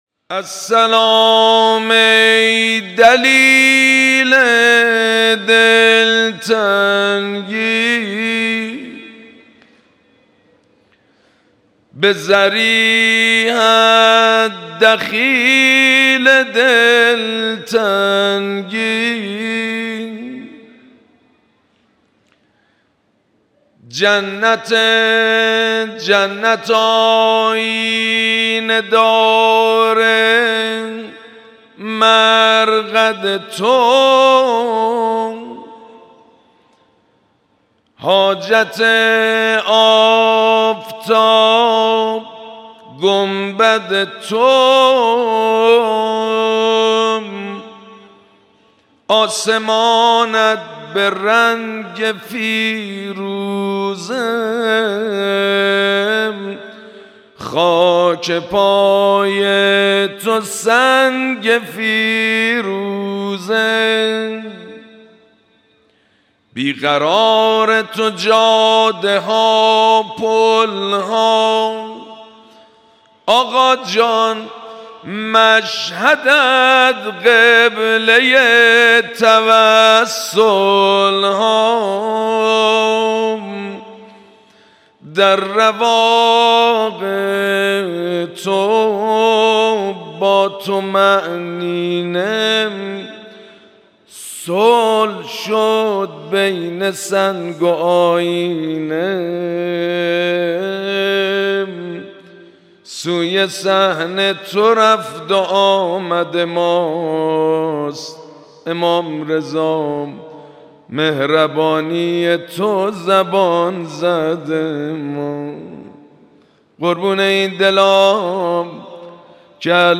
مراسم عزاداری ظهر سی‌ام ماه صفر شنبه ۲ شهریور ۱۴۰۴ | ۳۰ صفر ۱۴۴۷ حسینیه امام خمینی (ره)
سبک اثــر شعر خوانی مداح حاج سید مجید بنی فاطمه